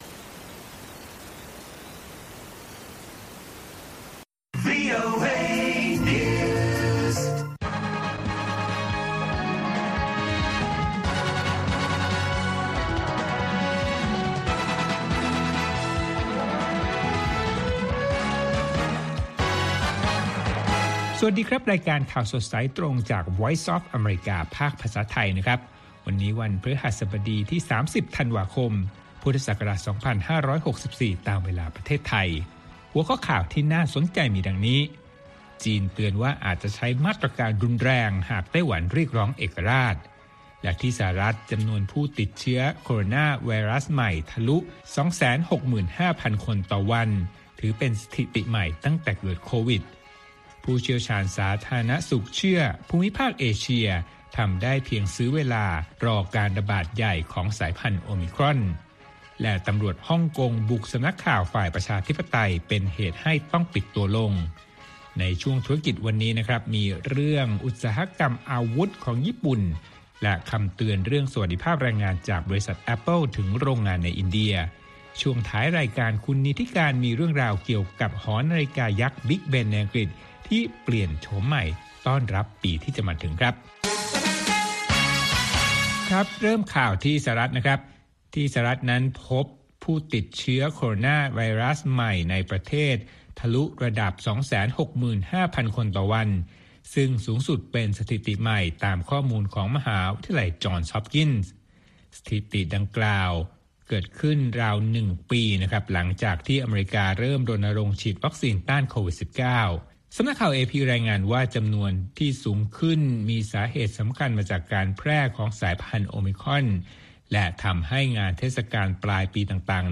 ข่าวสดสายตรงจากวีโอเอ ภาคภาษาไทย 6:30 – 7:00 น. ประจำวันพฤหัสบดีที่ 30 ธันวาคม2564 ตามเวลาในประเทศไทย